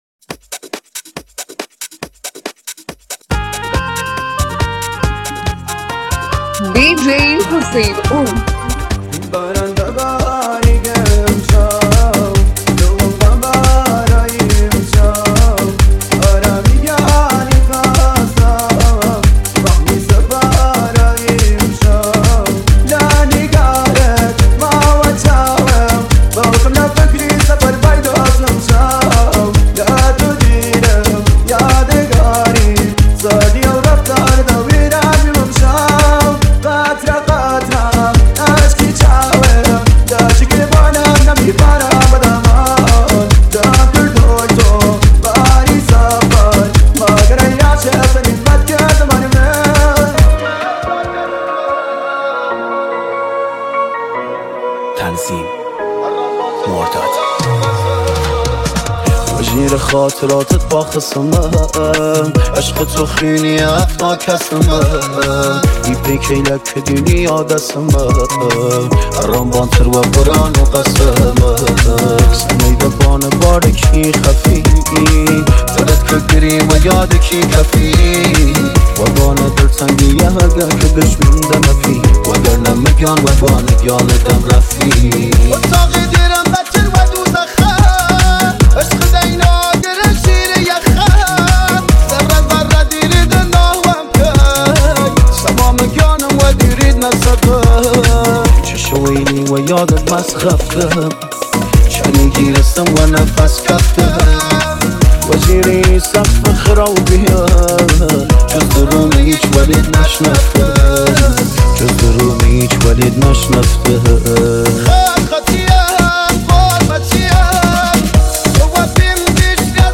آهنگ کردی غمگین